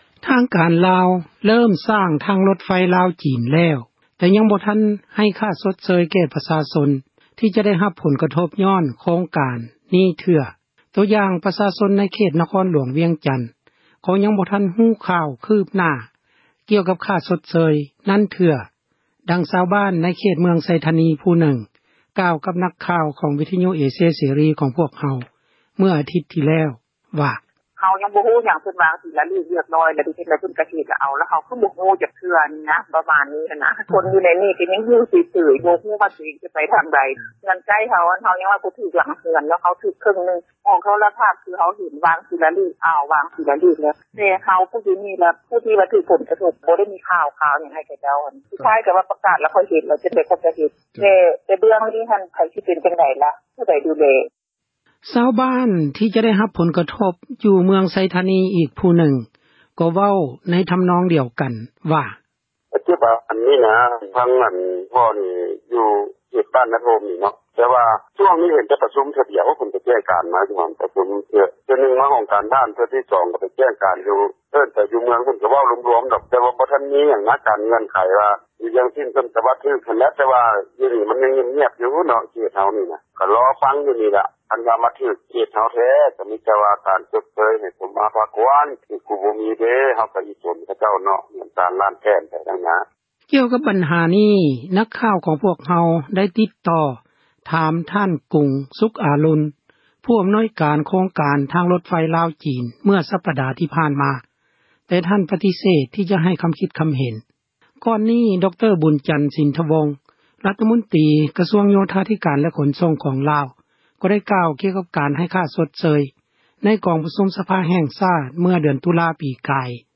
ຊາວບ້ານ ທີ່ຈະໄດ້ຮັບ ຜົນກະທົບ ຢູ່ເມືອງໄຊທານີ ອີກຜູ້ນຶ່ງ ກໍເວົ້າໃນທຳນອງ ດຽວກັນວ່າ: